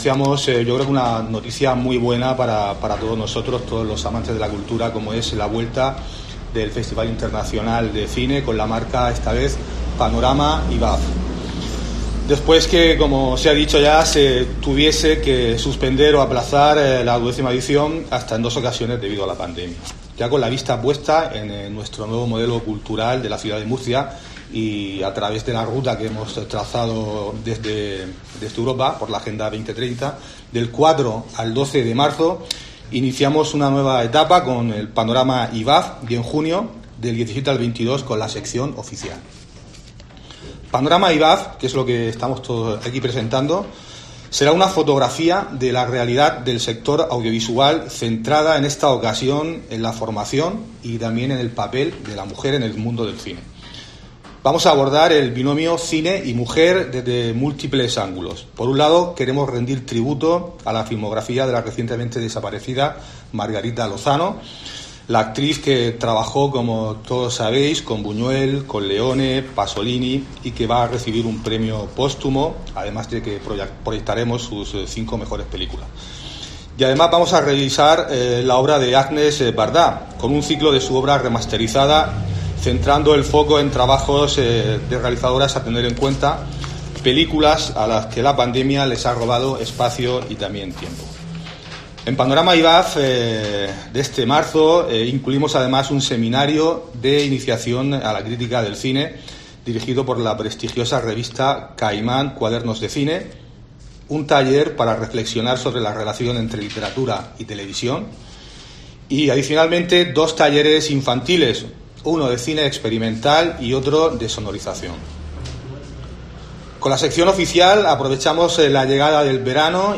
Pedro García Rex, concejal de Cultura, Turismo y Deportes